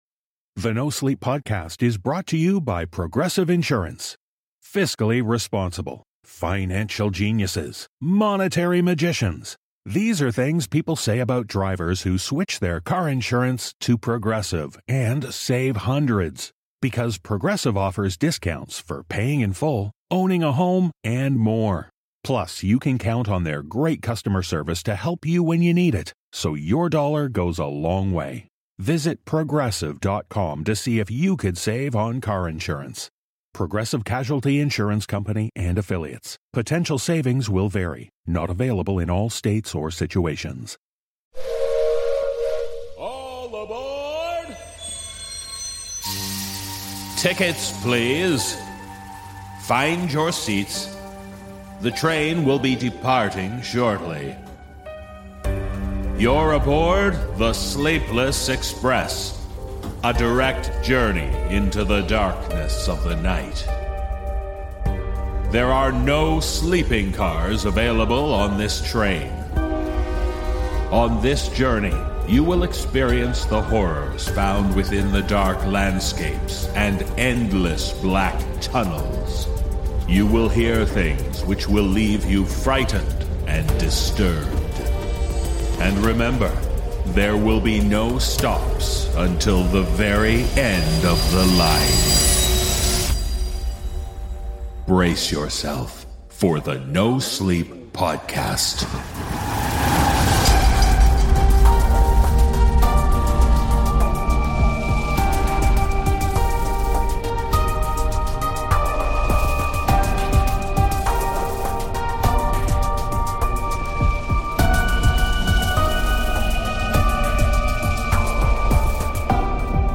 Musical score